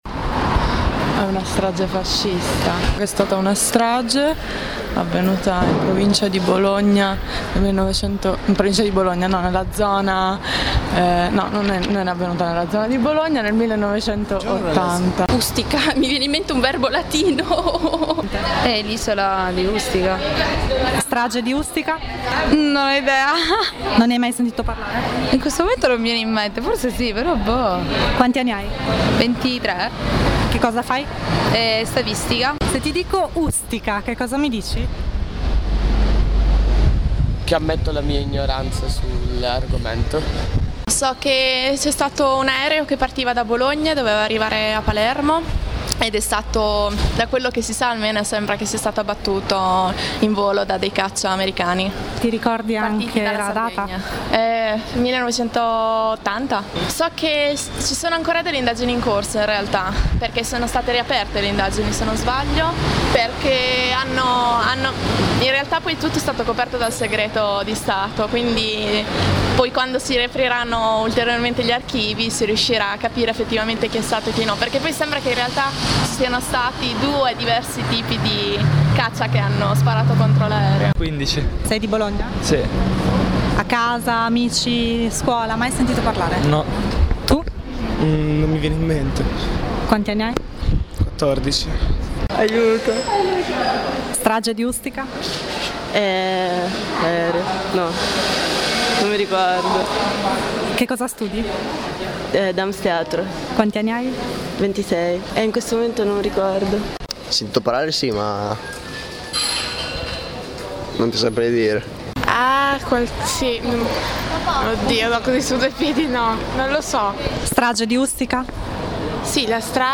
Abbiamo raccolto solo alcune voci, che speriamo non siano rappresentative di tutti i giovani che vivono nella nostra città.